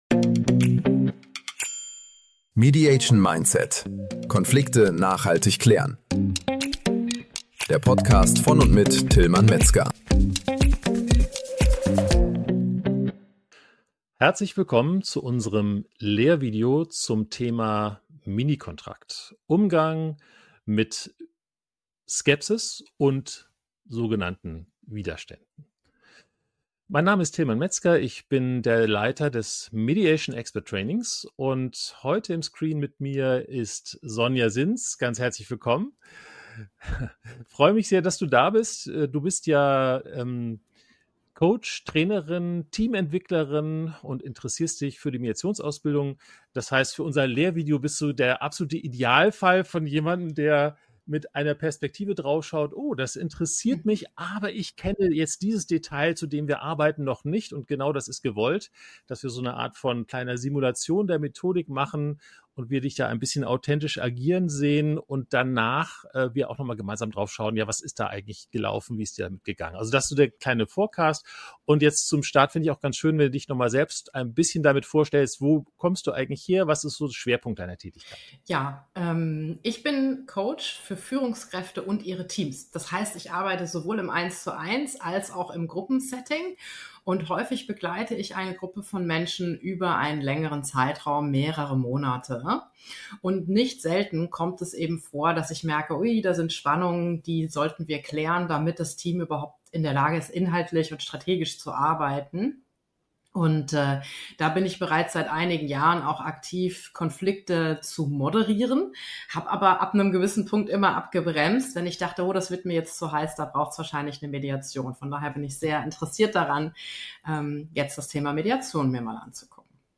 Im lebendigen Rollenspiel wird gezeigt, wie Führungskräfte, Mediator:innen und Teams typischen Einwänden begegnen können: Warum eigentlich externe Hilfe?